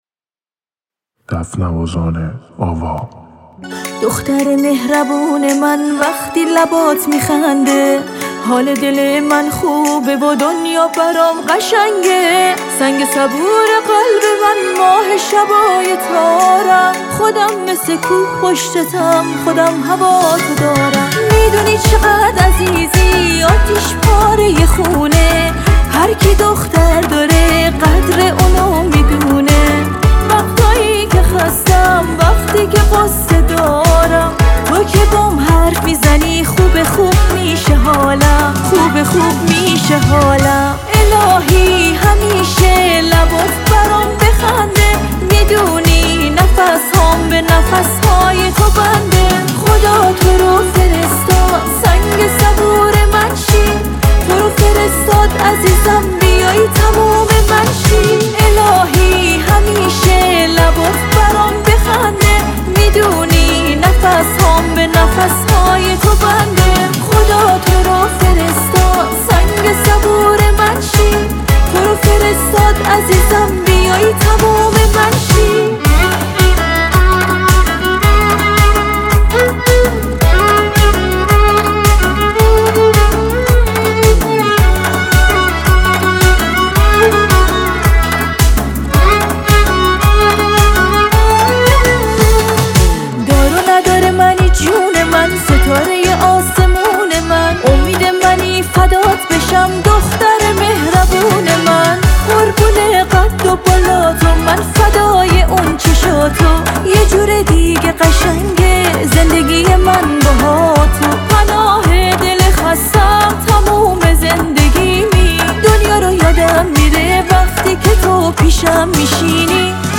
احساسی